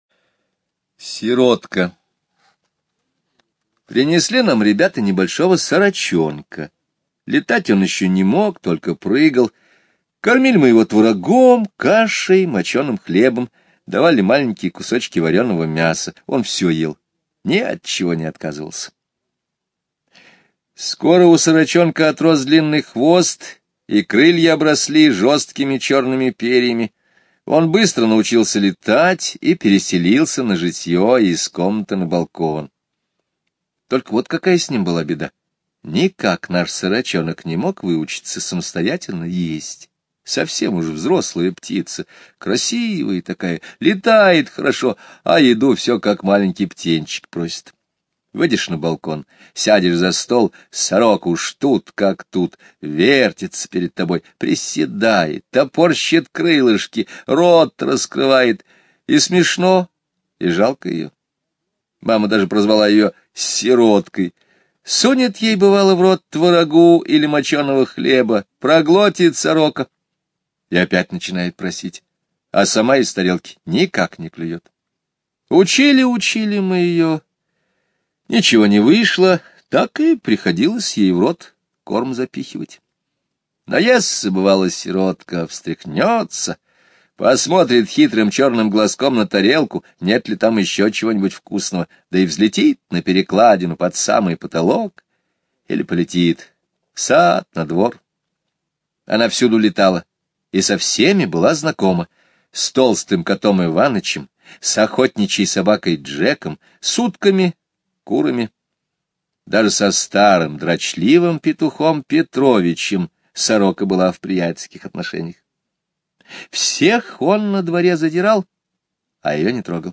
Слушайте аудио рассказ "Сиротка" Скребицкого Г. онлайн на сайте Мишкины книжки. Однажды ребята принесли в дом маленького сорочонка, который не мог еще летать.